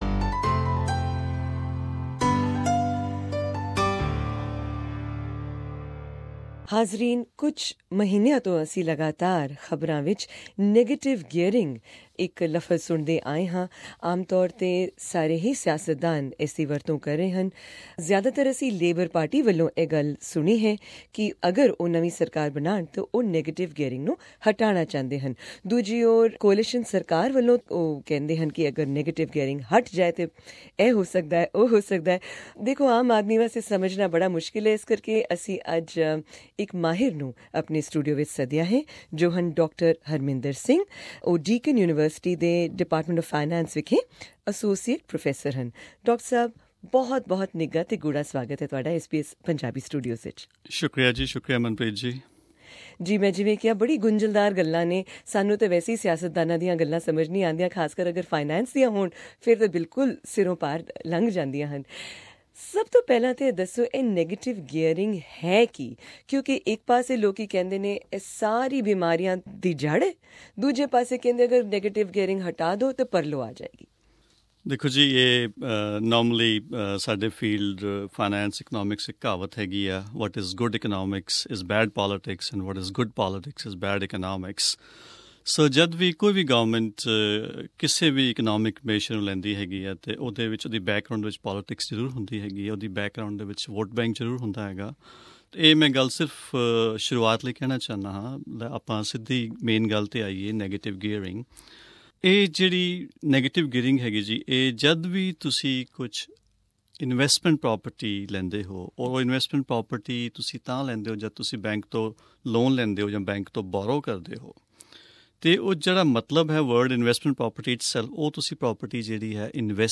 With Australian leaders of all political hues saying almost contradictory things about "negative gearing", we invited an expert into the SBS Punjabi studios to explain it all.